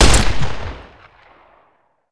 wpn_pistol10mm_fire_3d.wav